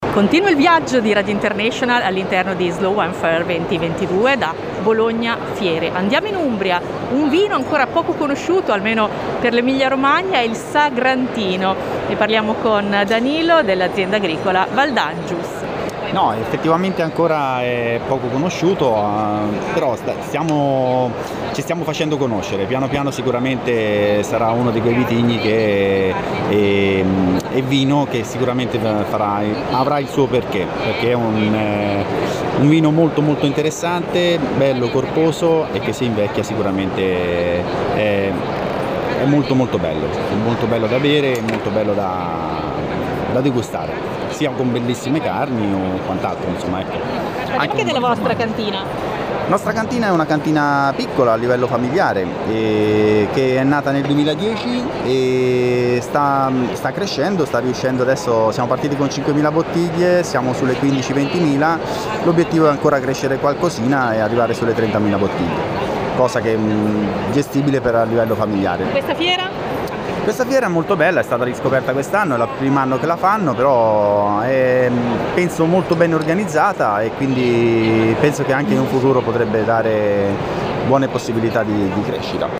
Slow Wine Fair – Fiera di Bologna – 27-28-29 Marzo
intervista al Azienda Agricola Valdangius di Montefalco (PG)